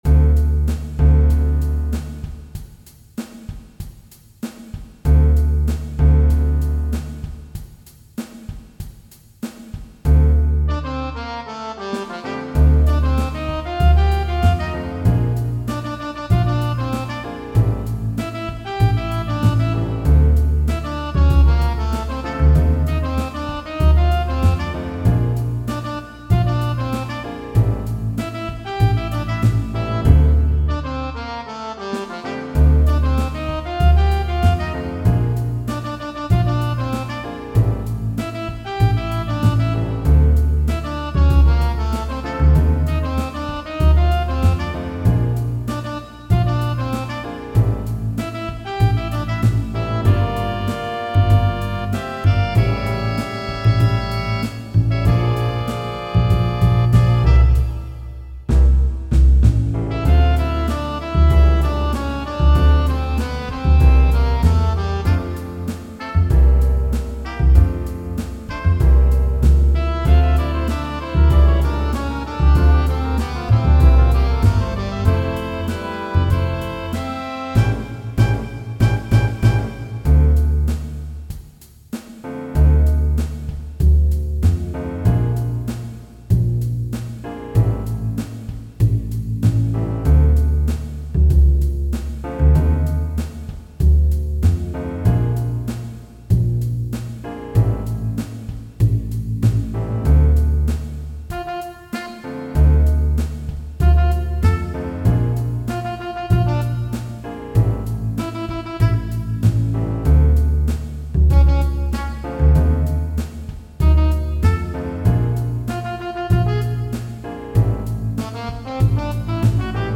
All audio files are computer-generated.
Optional bass and piano comping in solo section.